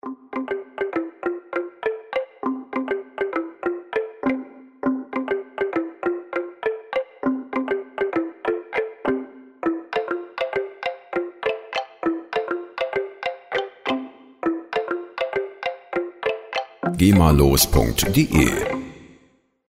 Woodblock
Der Klang der Musikinstrumente
Instrument: Schlaginstrument
Tempo: 100 bpm
woodblock.mp3